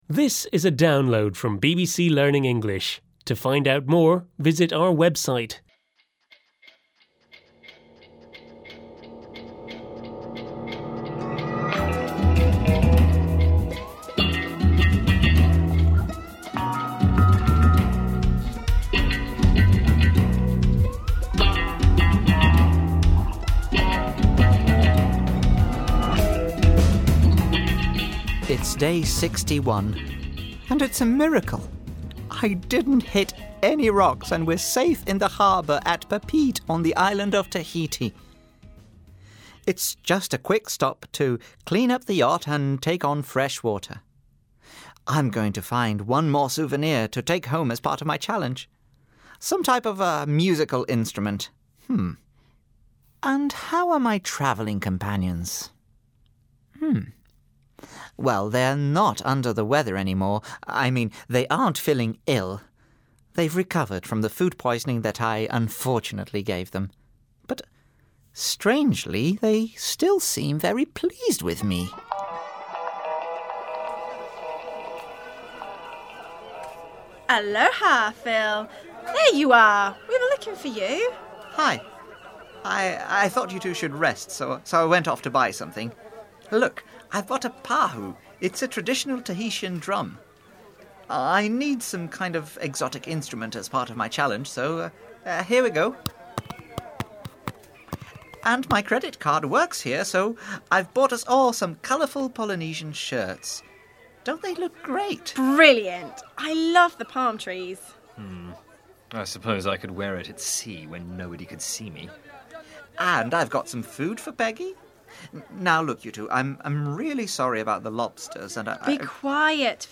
unit-9-5-1-u9_eltdrama_therace_download.mp3